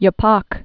(yə-pŏk)